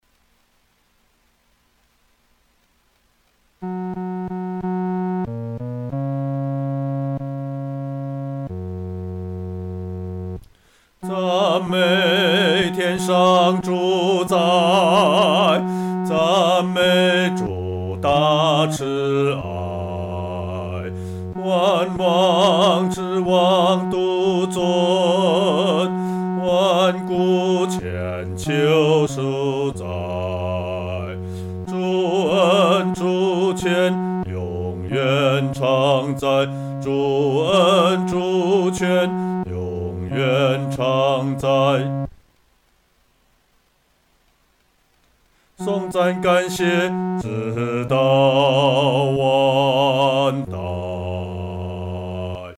独唱（第四声）
曲调欢快、明朗